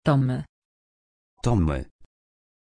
Pronuncia di Tommy
pronunciation-tommy-pl.mp3